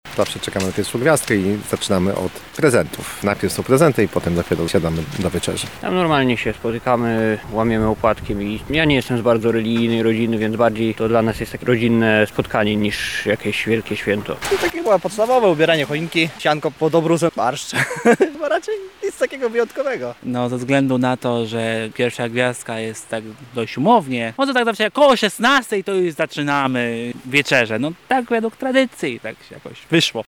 Zapytaliśmy mieszkańców Lublina, jakie zwyczaje świąteczne dalej funkcjonują w ich domach:
SONDA